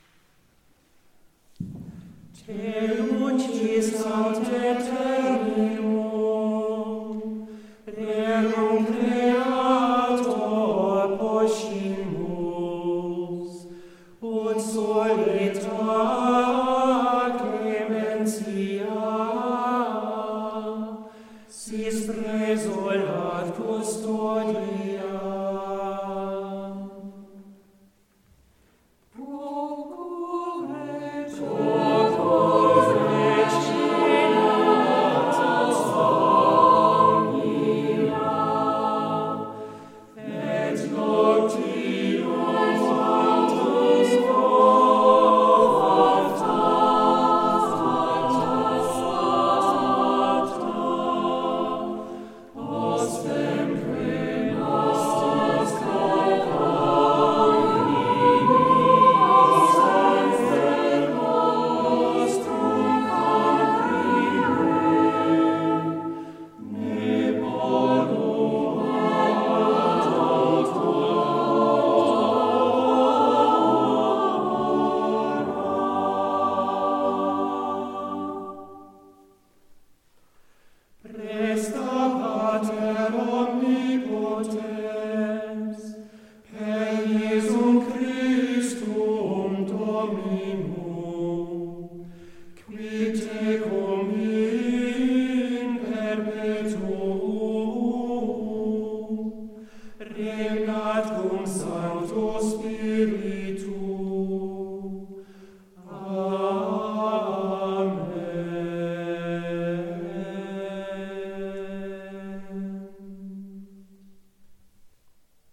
Klang - Chor Vokalensemble Capella Moguntina, Mainz